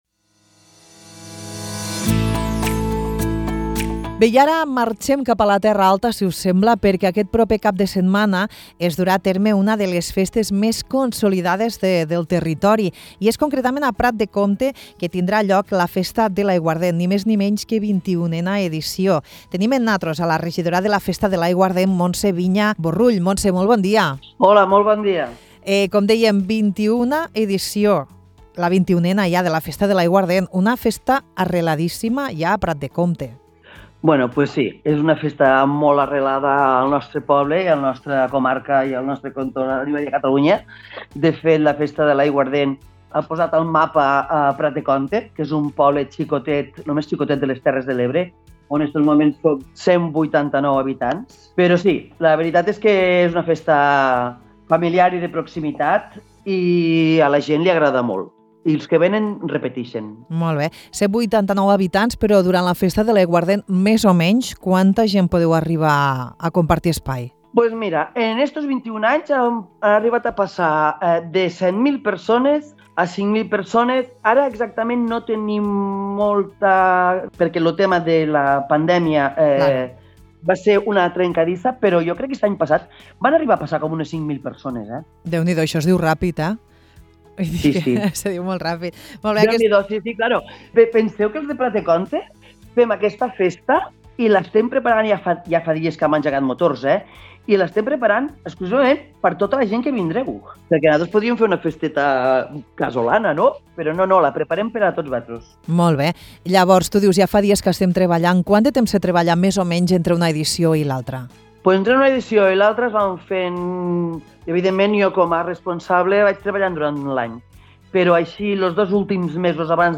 Amb la regidora de la Festa de l’Aiguardent, Montse Viña Borrull, parlem sobre aquesta 21a edició de la festa, que se celebrarà el 12 i 13 d’octubre a la població de la Terra Alta.